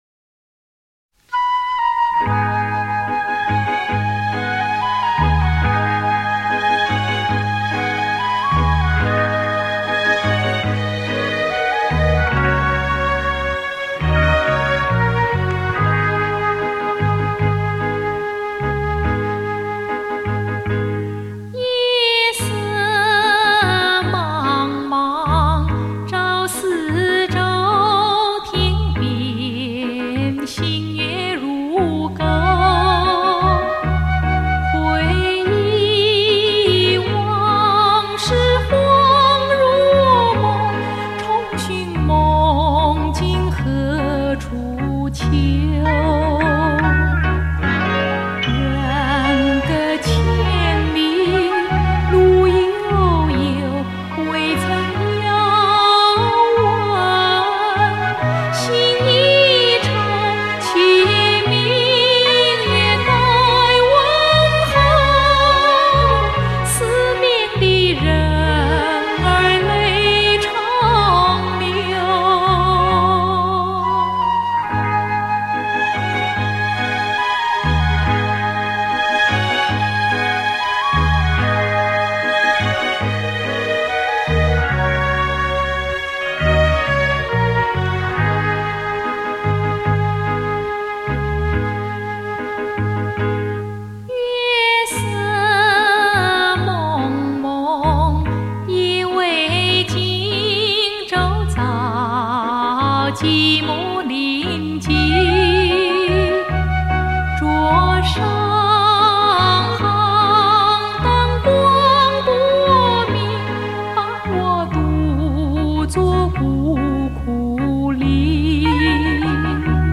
发烧录音专辑